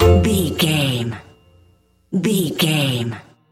Ionian/Major
F#
Slow
orchestra
strings
flute
drums
circus
goofy
comical
cheerful
perky
Light hearted
quirky